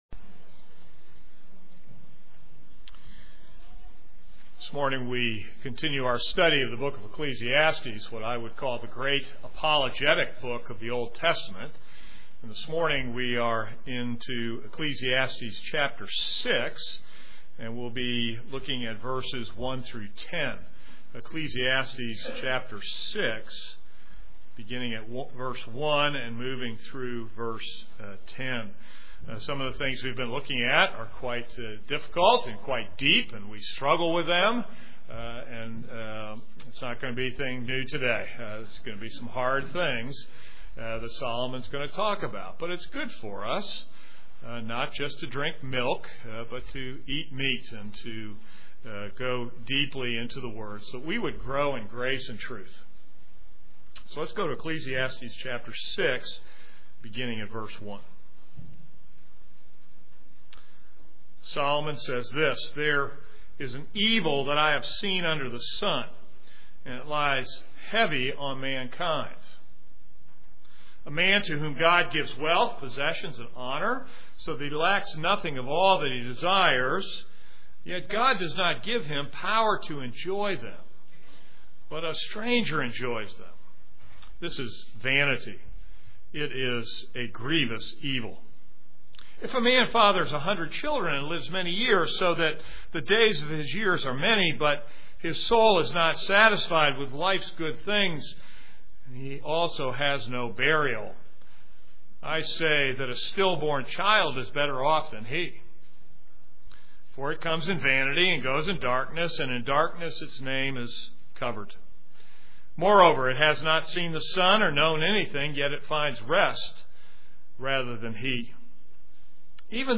This is a sermon on Ecclesiastes 6:1-10.